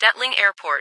- New ATIS Sound files created with Google TTS en-US-Studio-O